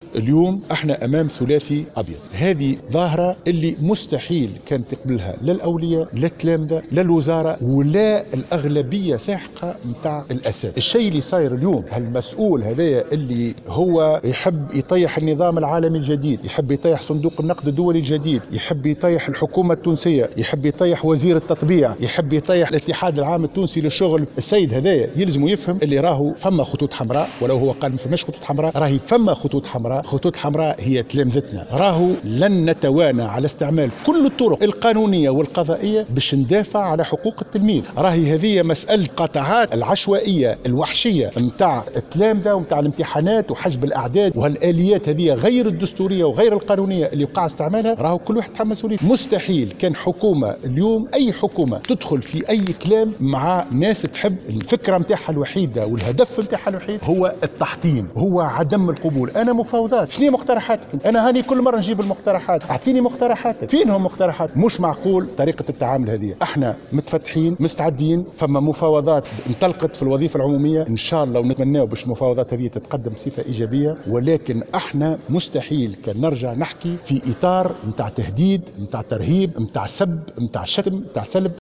قال وزير التربية حاتم بن سالم، في تصريح لمراسلة الجوهرة اف ام اليوم الاثنين، إن المنظومة التربوية التونسية تعيش في إطار غير مسبوق.